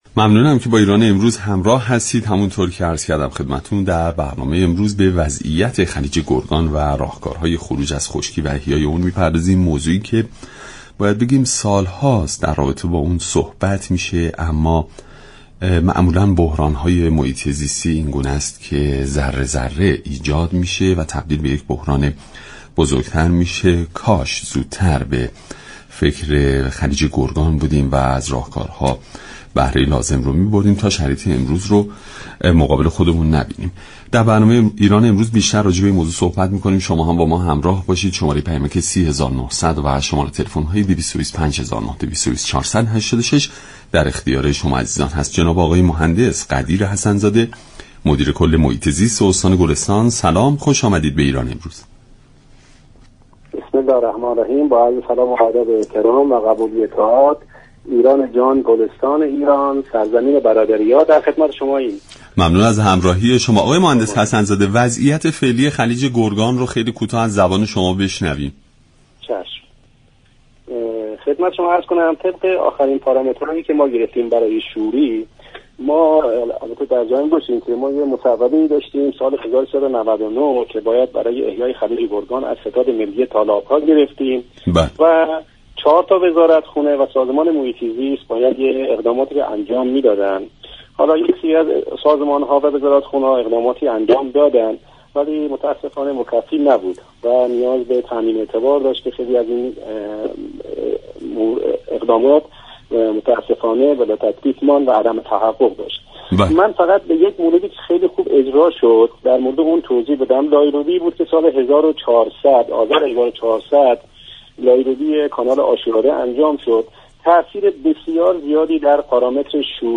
مدیركل محیط زیست استان‌گلستان در برنامه ایران‌امروز گفت:در آبان امسال شوری آب خلیج‌گرگان به 33 گرم در لیتر رسیده است.